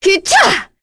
Naila-Vox_Attack4.wav